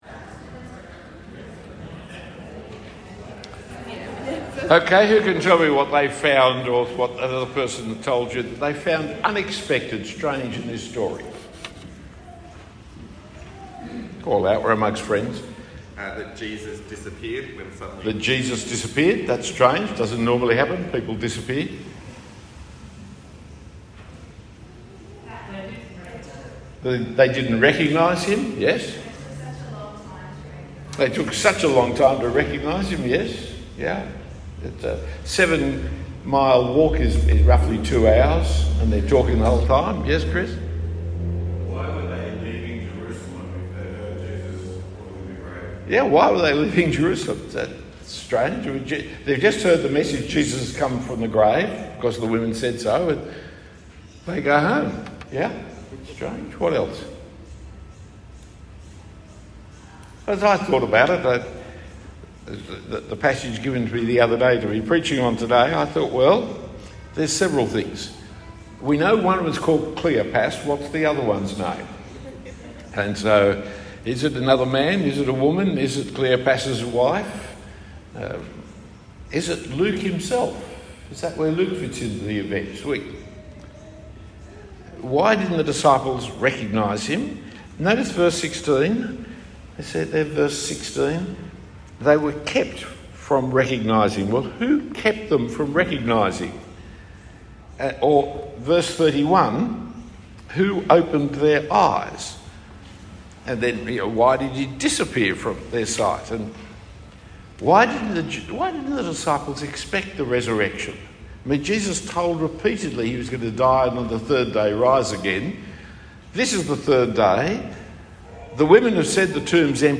A talk given at St Nicolas Coogee.